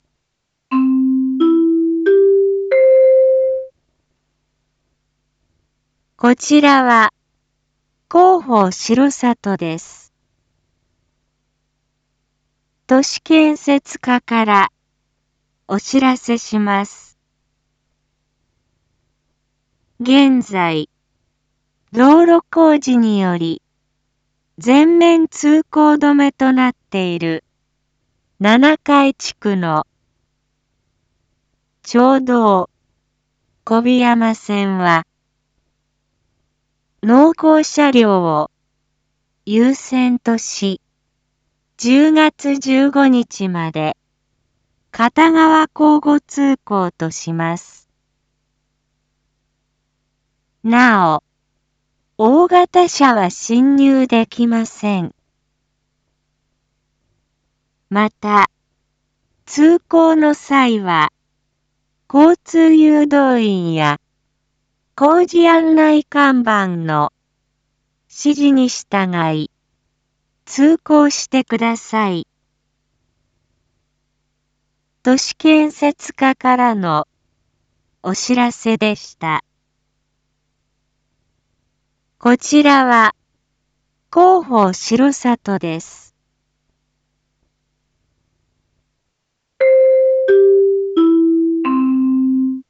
一般放送情報
Back Home 一般放送情報 音声放送 再生 一般放送情報 登録日時：2023-10-15 07:01:34 タイトル：町道４号線の片側車線一時開放について インフォメーション：こちらは、広報しろさとです。